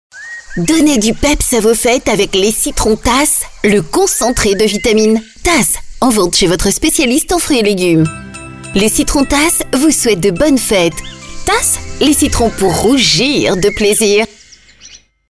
Spot radio TAS